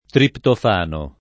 [ triptof # no ]